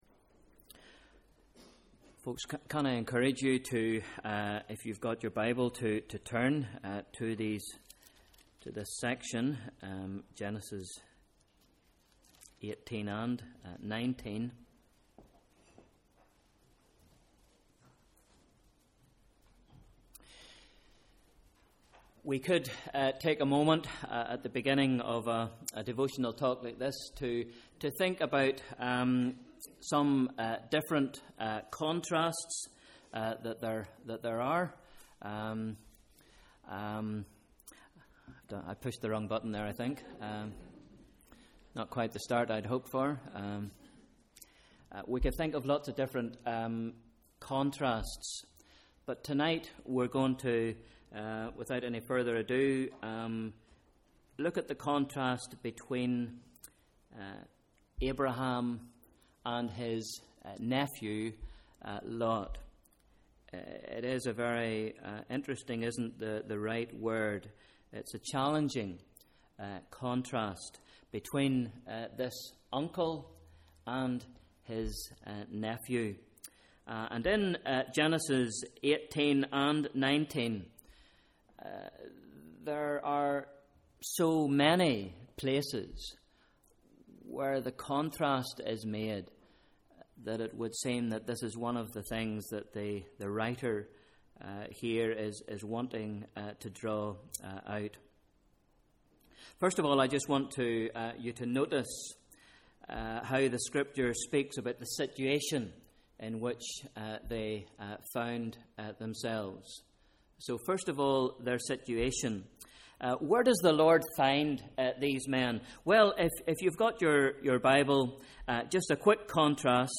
Evening Worship: Sunday 10th November 2013 Bible Reading: Genesis 19